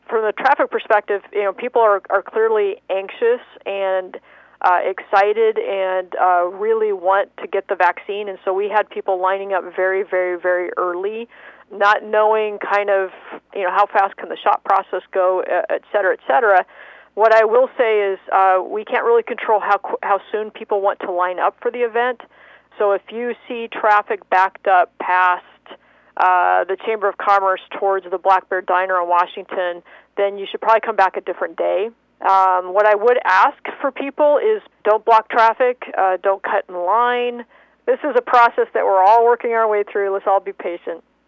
Sequim Police Chief Sheri Crain was on the scene Thursday morning and had this message for those planning to come back on one of the later days.